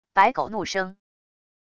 白狗怒声wav音频